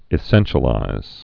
(ĭ-sĕnshə-līz)